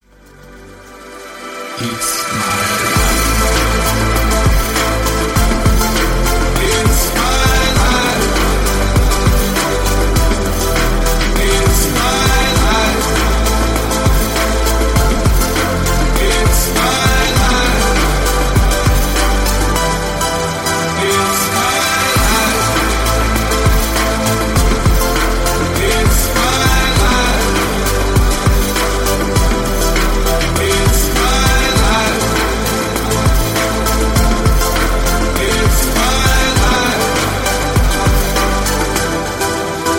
• Качество: 128, Stereo
retromix
медленные
ремиксы